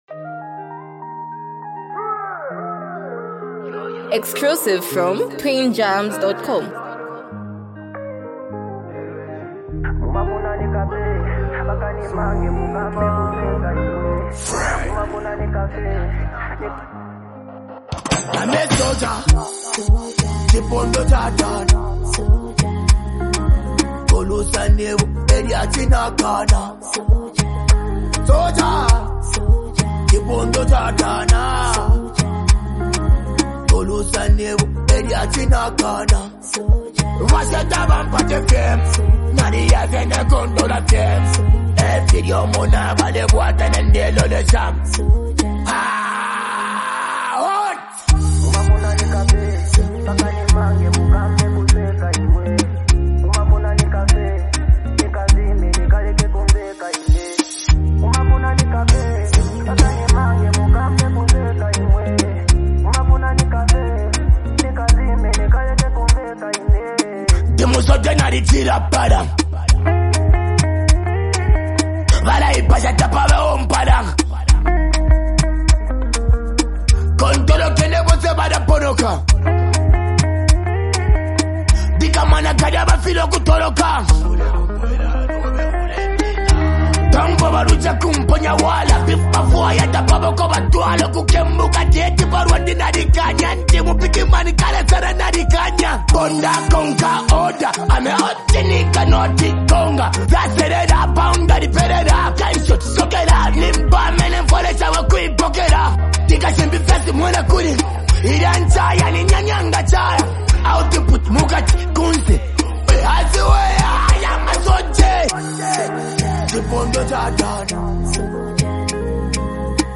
raw and emotional street anthem